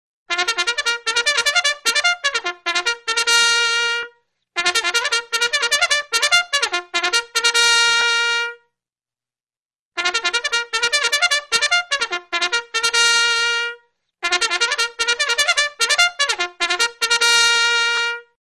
Горн Для тех, кто не забыл, как он звучит.